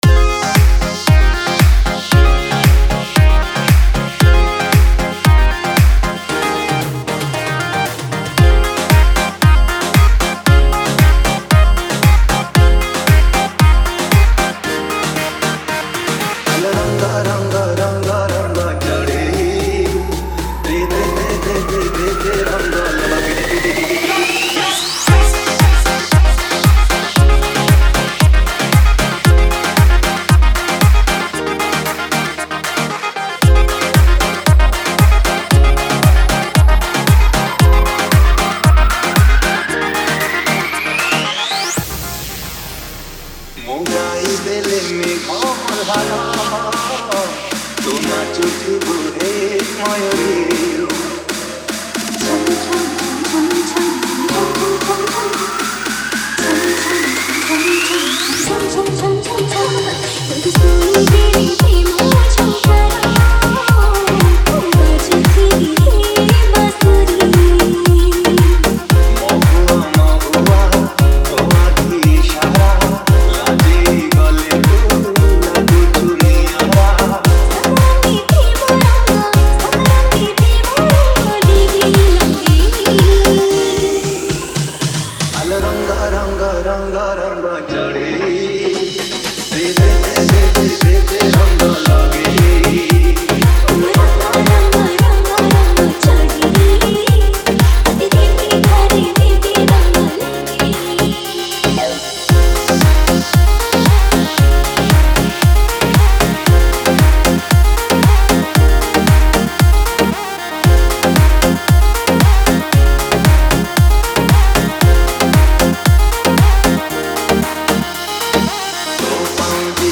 Romantic Love Dj Remix Songs Download
Romantic Love Dj Remix